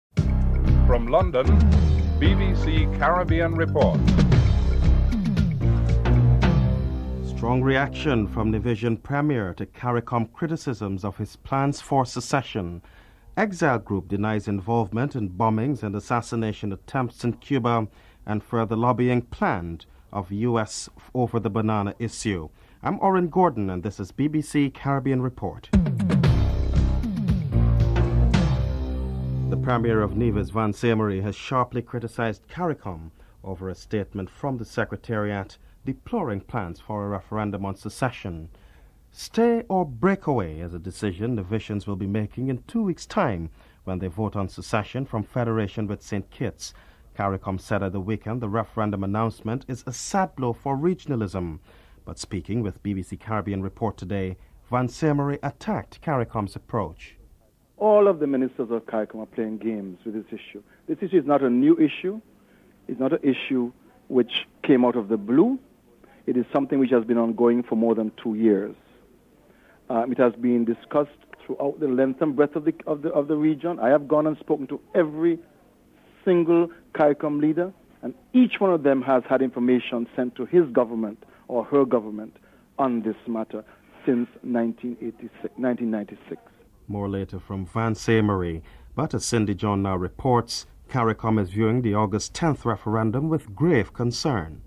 A member of the group the Cuban American National Foundation is interviewed (05:10-07:59)